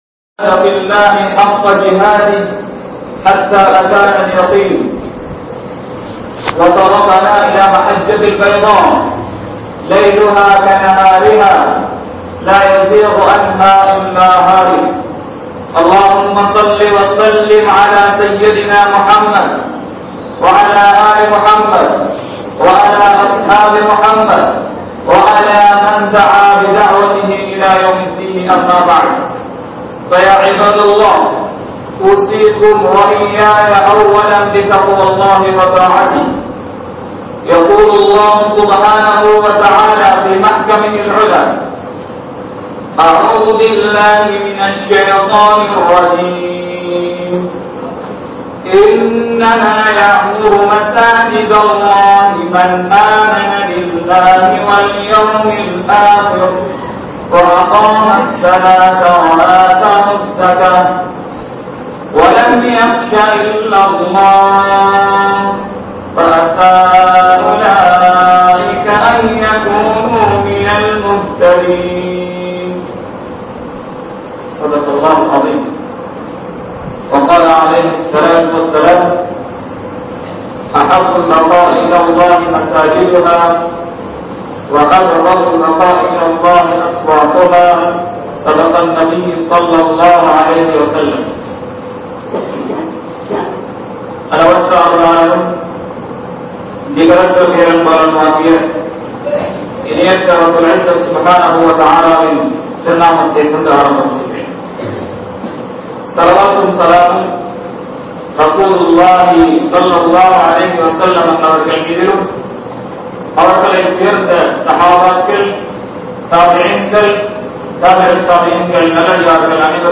Palli Niruvaaha Safaikku Yaar Thahuthi?? (பள்ளி நிருவாக சபைக்கு யார் தகுதி?) | Audio Bayans | All Ceylon Muslim Youth Community | Addalaichenai